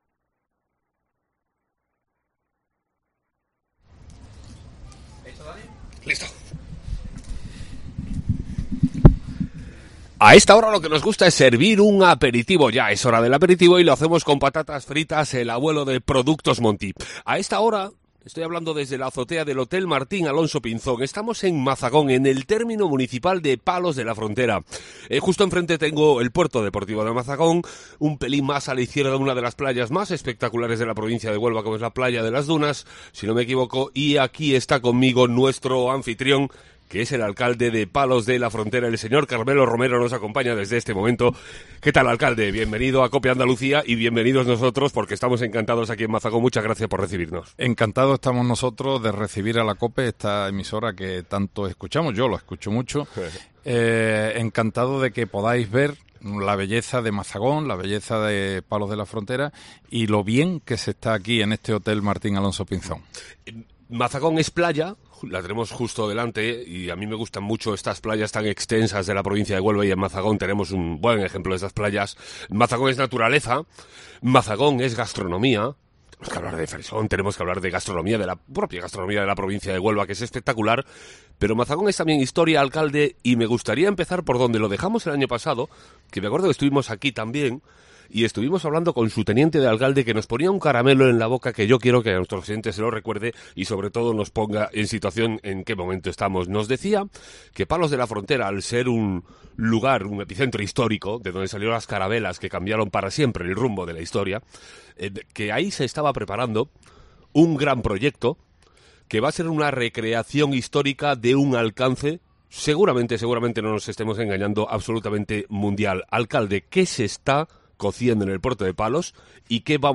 La sombrilla de COPE Andalucía llega a la Playa de las Dunas de Mazagón, su primera etapa onubense.